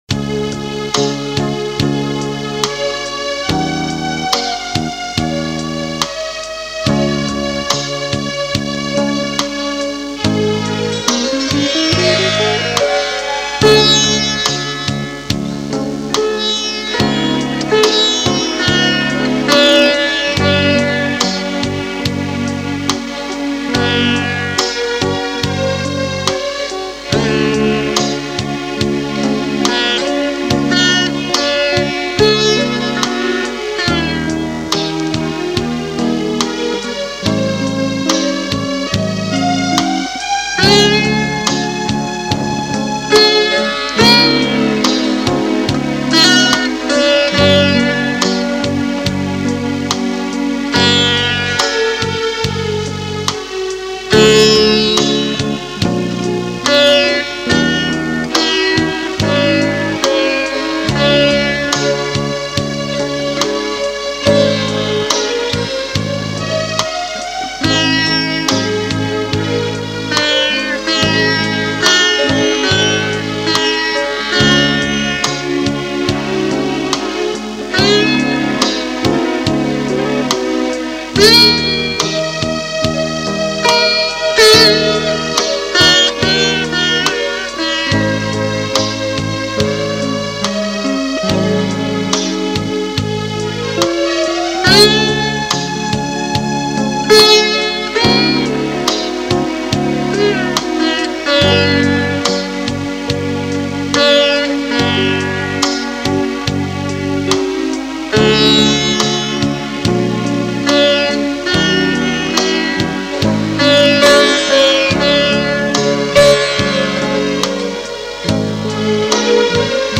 Gospel Melody in Jazz Style.
Sitar
Keyboards
Guitar